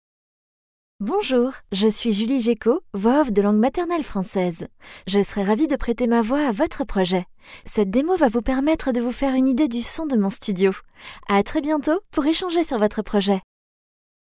Female
20s, 30s
Assured, Bright, Bubbly, Confident, Conversational, Cool, Corporate, Energetic, Engaging, Friendly, Funny, Natural, Reassuring, Smooth, Soft, Streetwise, Upbeat, Versatile, Young
Corporate.mp3
Microphone: Neumann TLM103
Audio equipment: Soundproof cabin Studiobricks One Plus, Pream Universal Audio Solo 610